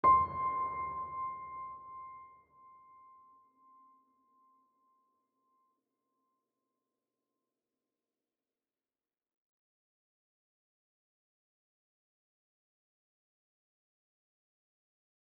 piano-sounds-dev
c5.mp3